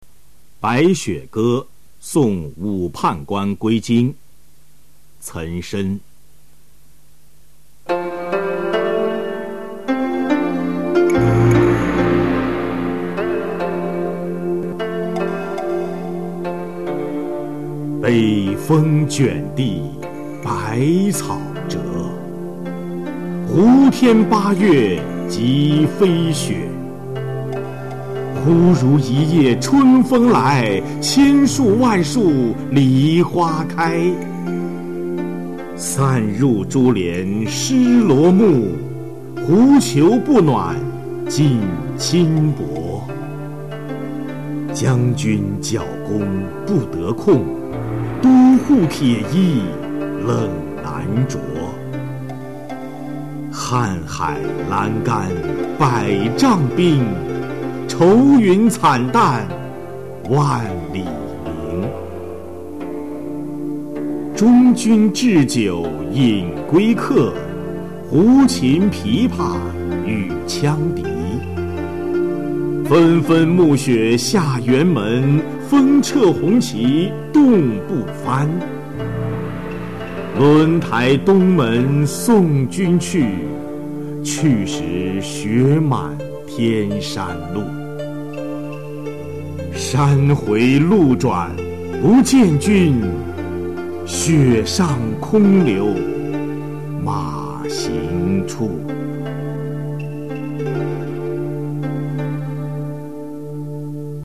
岑参《白雪歌送武判官归京》原文与译文（含鉴赏、朗读）　/ 岑参